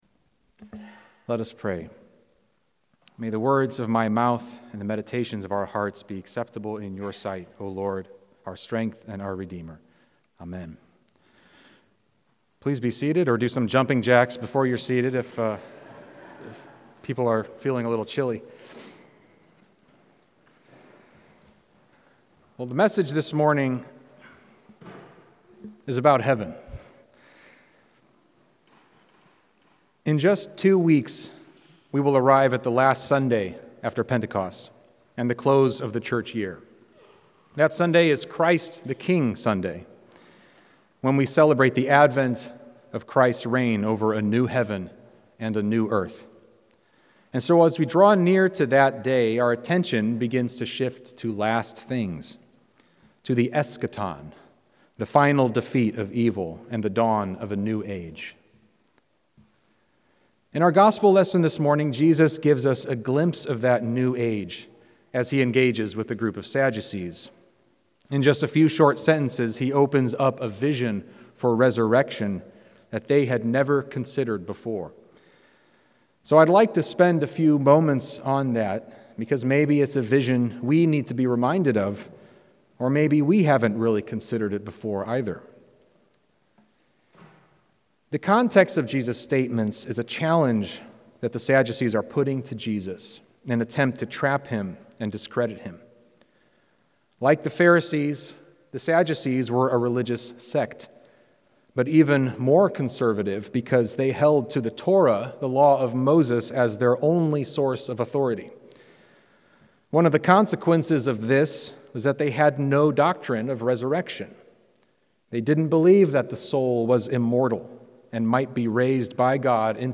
Sermons | Church of the Good Shepherd